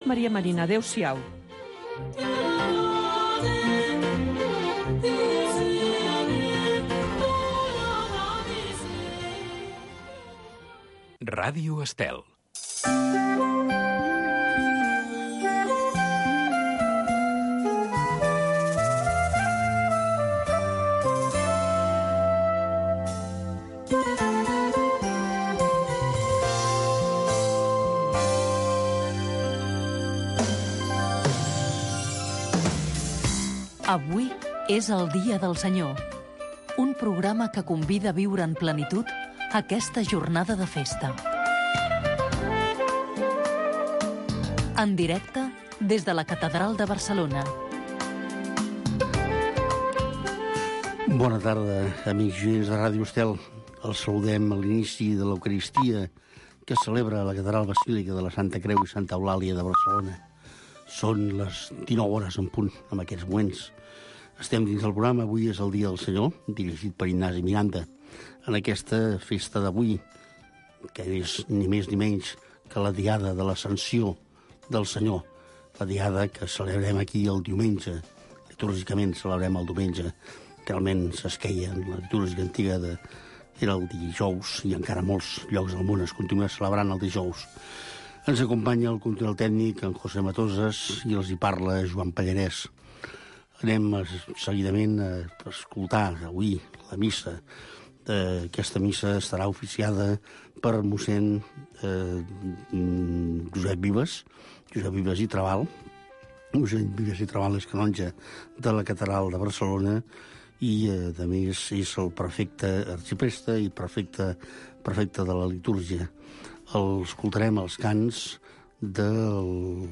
s de la catedral de Barcelona es retransmet tots els diumenges i festius la missa, precedida d’un petit espai d’entrevista